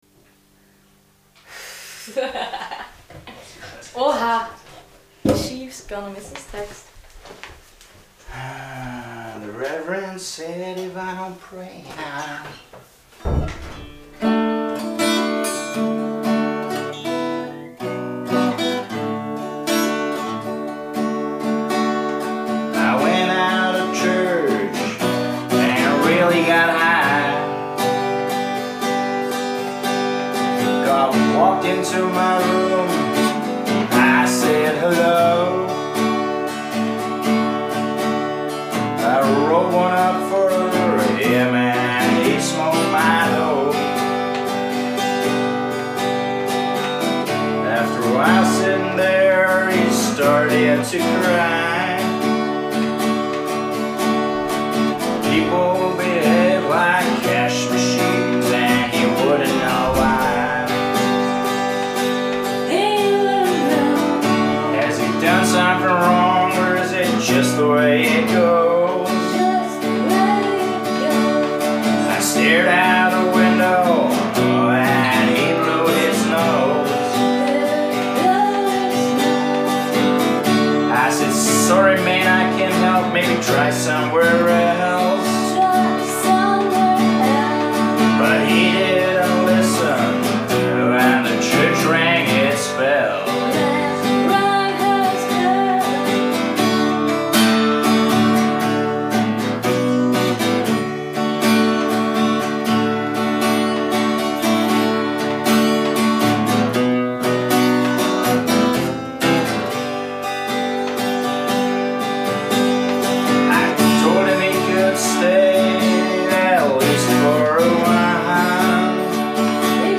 Sessions Nichts geht über live eingespielte Stücke, am Besten sogar improvisiert und gut geraten....
Eine Wohnküche, gutes Essen, Vino, Bier, Kippen, Akkustische Gitarren und ein Mikrophon... mehr nicht!